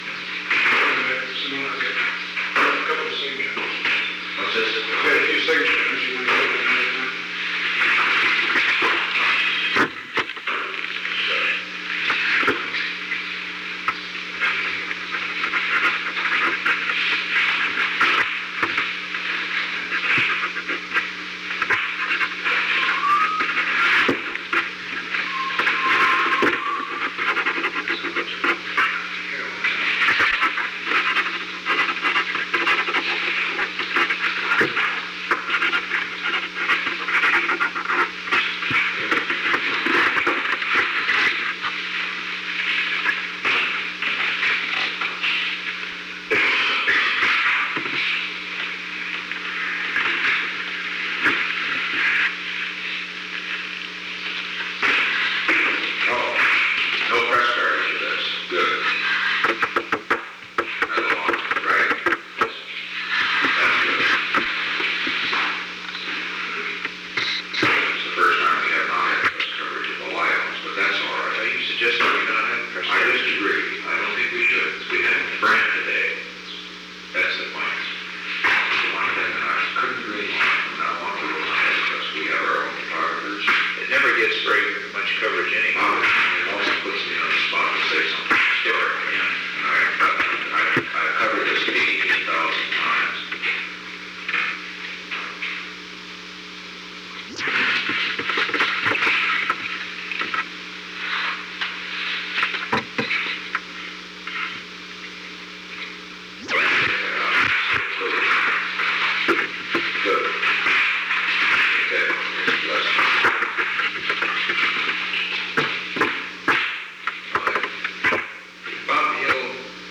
Conversation No. 520-10 Date: June 15, 1971 Time: Unknown between 12:54 pm and 2:15 pm 27 NIXON PRESIDENTIAL MATERIALS STAFF Tape Subject Log (rev. 10/08) Location: Oval Office The President met with Alexander P. Butterfield President’s schedule -Signatures Butterfield left at 12:58 pm Conv.
Secret White House Tapes